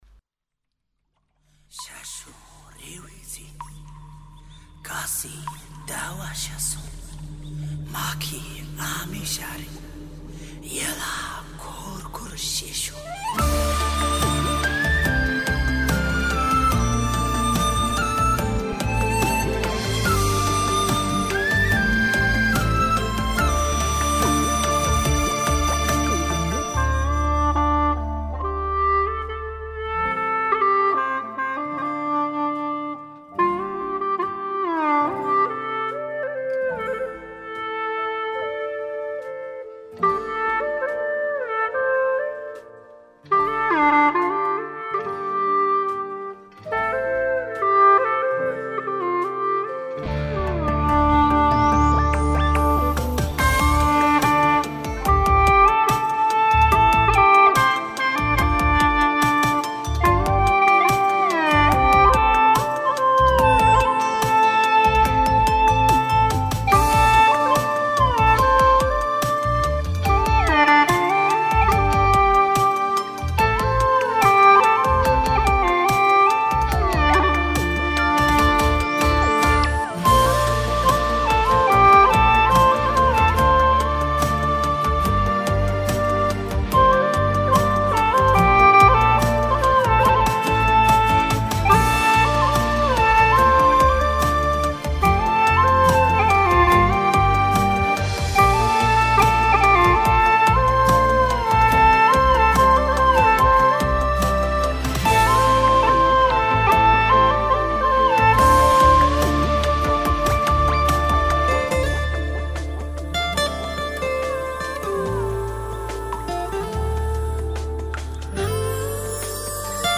调式 : F